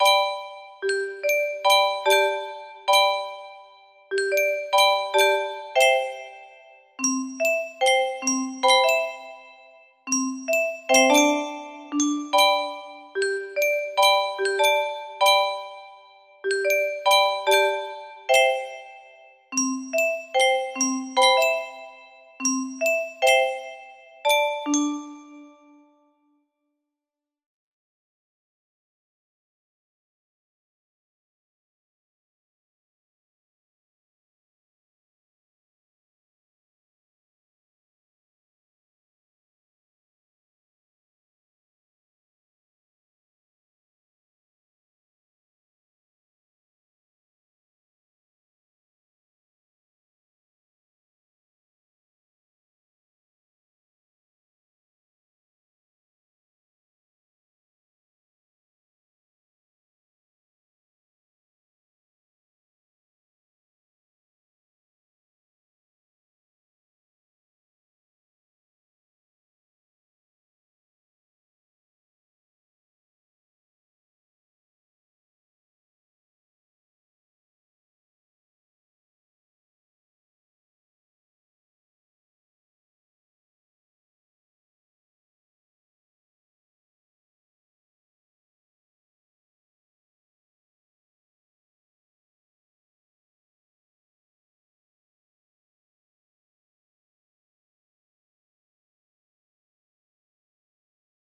for 15 notes